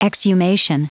Prononciation du mot exhumation en anglais (fichier audio)
Prononciation du mot : exhumation